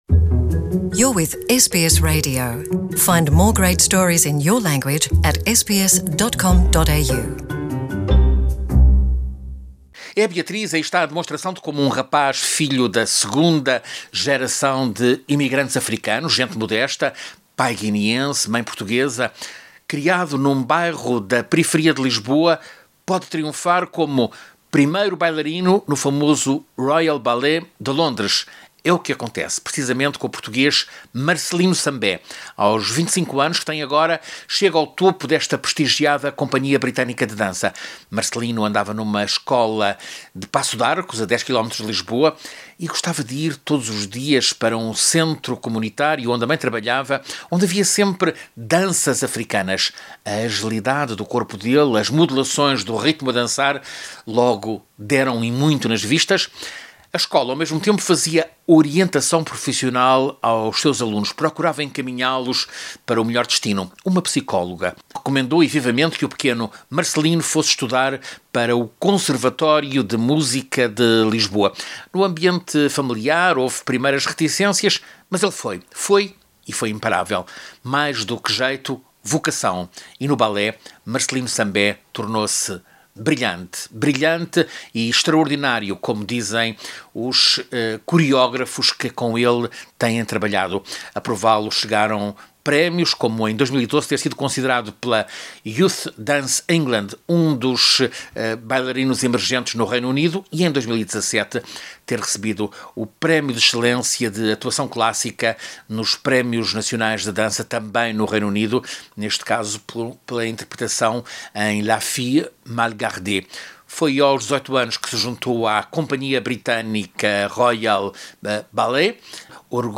Ouça a reportagem do correspondente da SBS